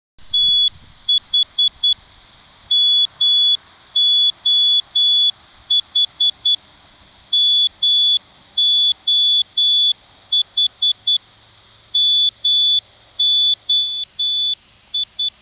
Aby biegacze nie mylili się i nie biegali w kółko, nadajniki emitują różne sygnały radiowe, tzw. identyfikatory, w kodzie Morse'a: Składa się on z dwóch długich tonów - Morse M, dwóch długich tonów - Morse O, trzech długich tonów oraz kolejnej litery i numeru nadajnika w tonach krótkich. Nadajnik 1 dodaje krótki ton, nadajnik 4 dodaje cztery.